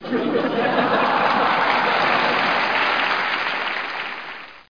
ampitheatre.mp3